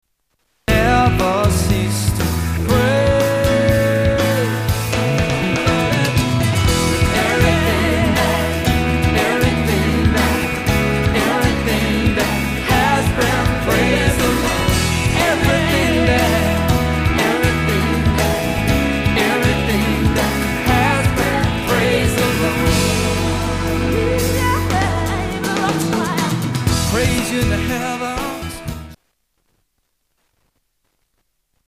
STYLE: Pop
rhythmic creativity